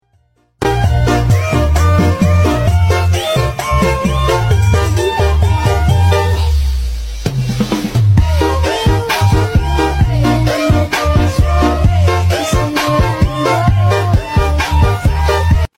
Rain Sound Effect Free Download
Rain